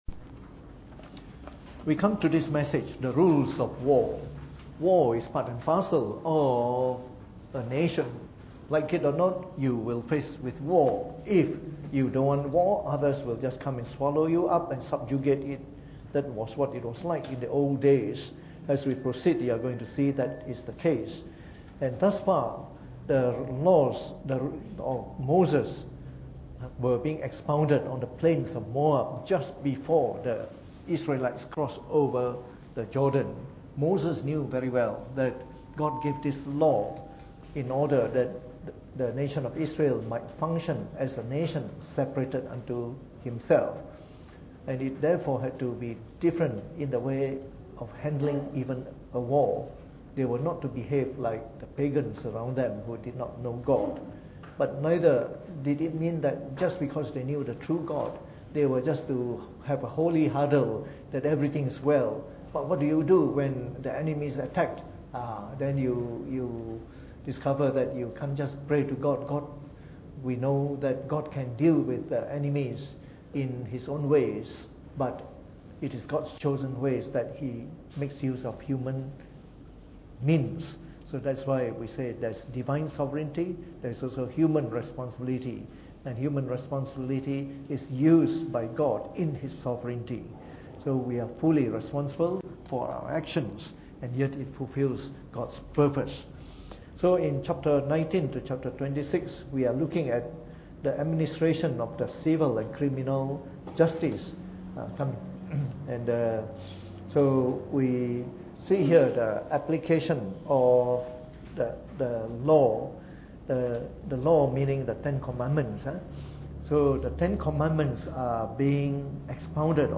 Preached on the 27th of June 2018 during the Bible Study, from our series on the book of Deuteronomy.